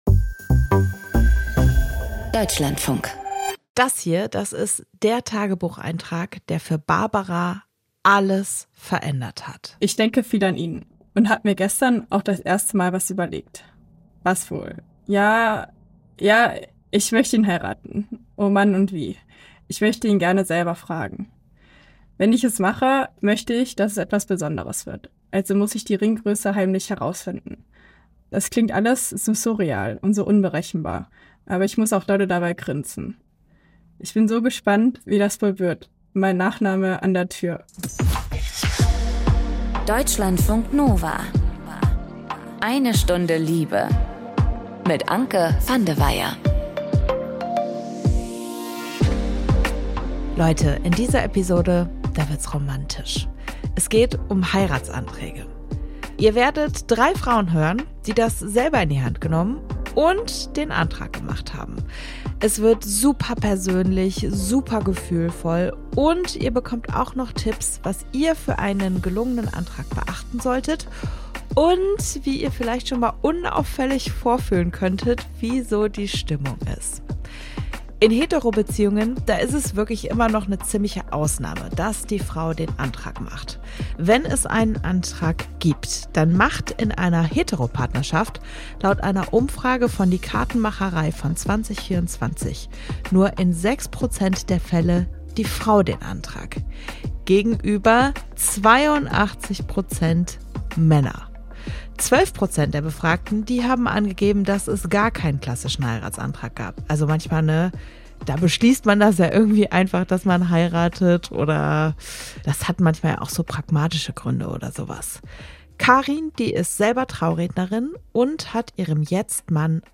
In dieser Episode erzählen Frauen, wie es lief, als sie selbst die Frage stellten: Willst du mich heiraten?